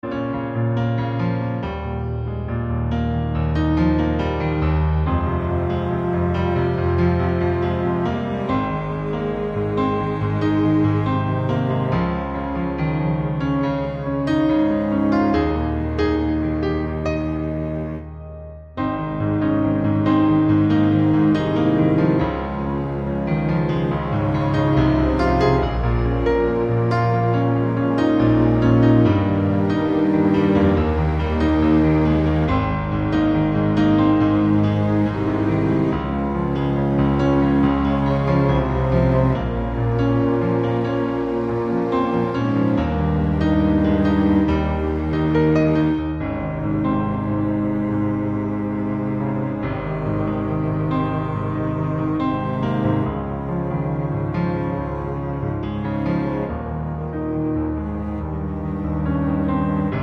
Four Semitones Down